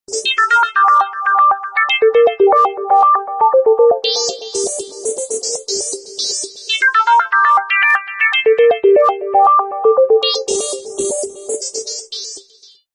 космос